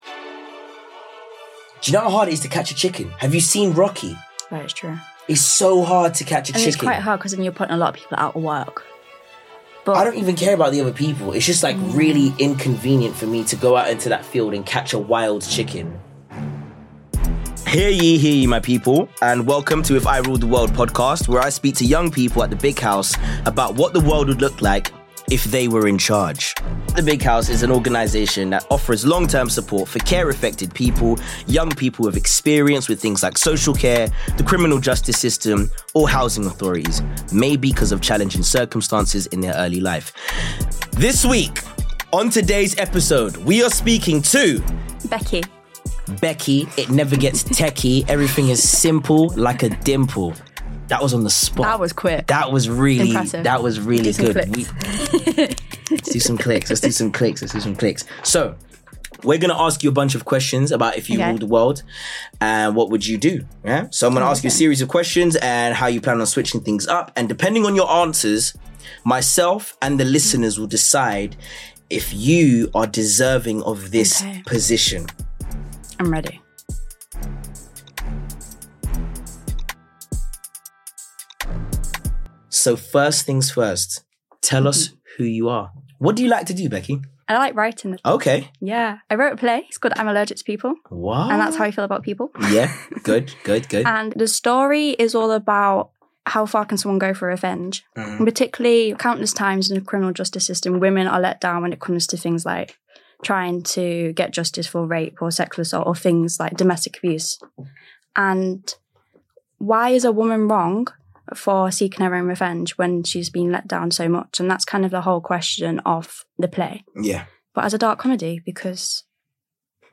Real voices.
# Conversations